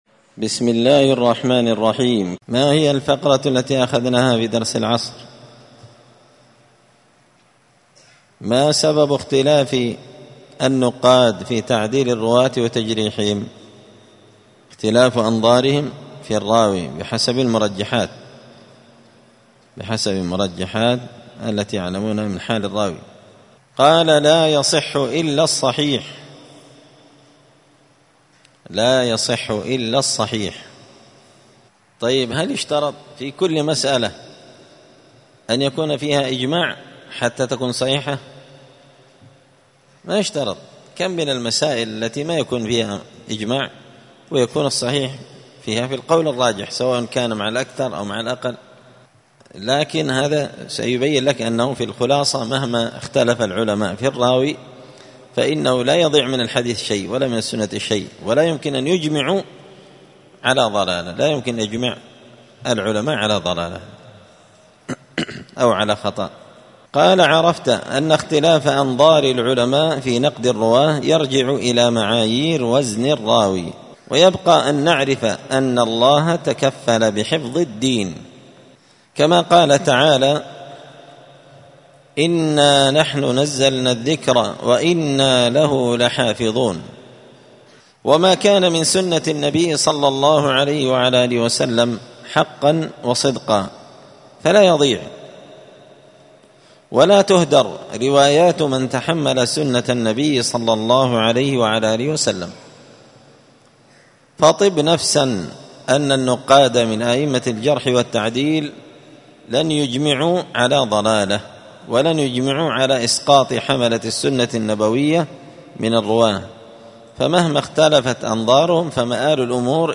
الجمعة 16 جمادى الآخرة 1445 هــــ | الدروس، المحرر في الجرح والتعديل، دروس الحديث وعلومه | شارك بتعليقك | 47 المشاهدات